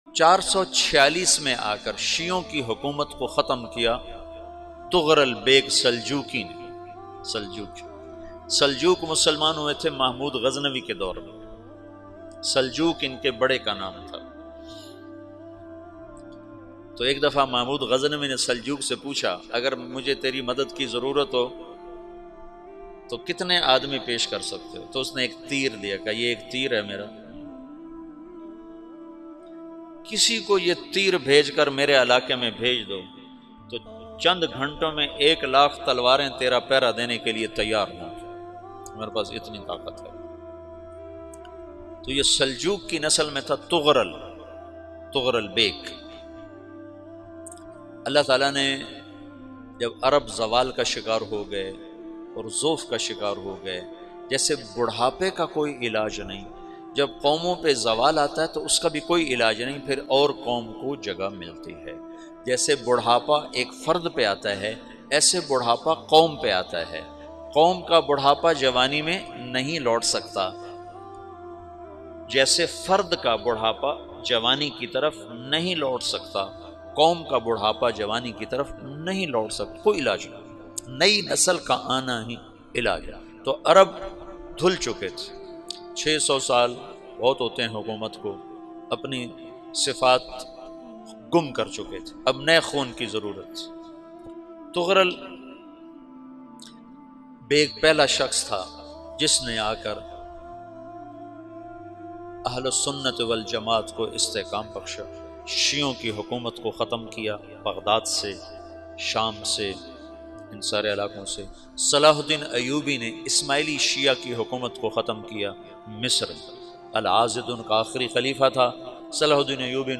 Ertugrul Kon Tha Molana Tariq Jameel Latest Bayan MP3